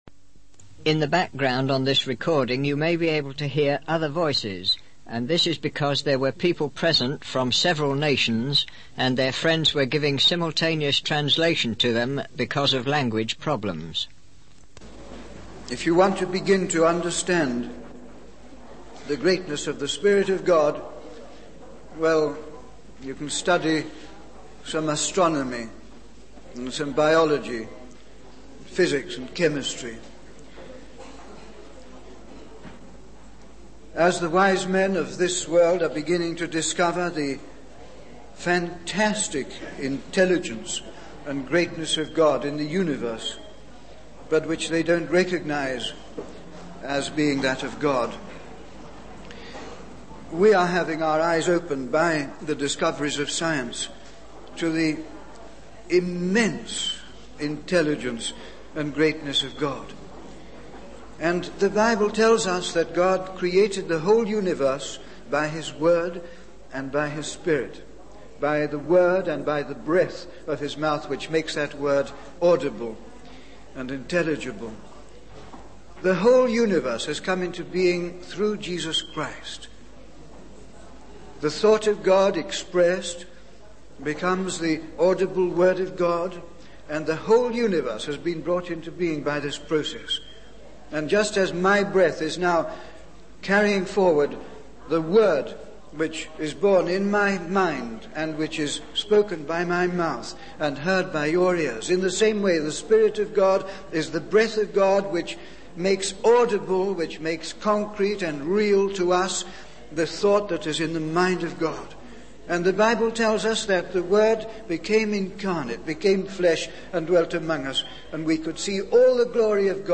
In this sermon, the speaker emphasizes the power and significance of making a contract or covenant. He compares the binding nature of signatures on a piece of paper to the vows made in marriage.